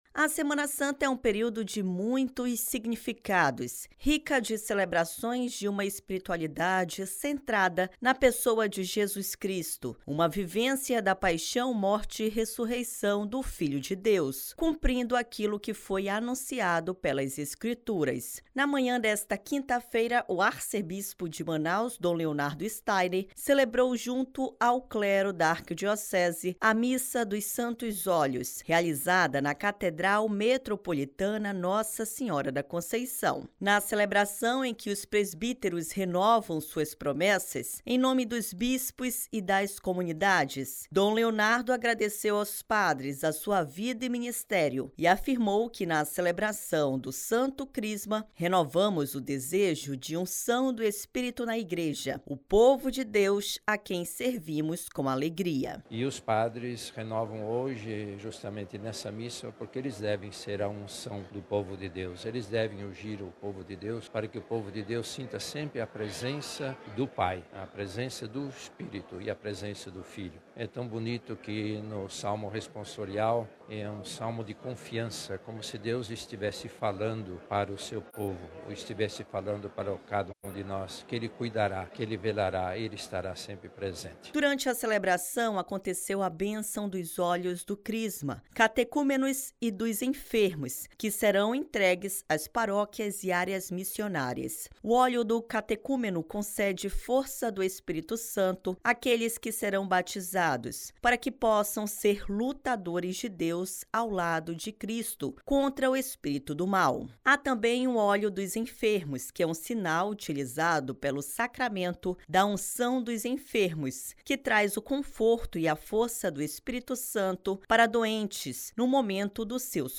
Na manhã desta quinta-feira, o Arcebispo de Manaus, Dom Leonardo Steiner celebrou, junto ao Clero da Arquidiocese, a Missa do Santos Óleos realizada na Catedral Metropolitana Nossa Senhora da Conceição.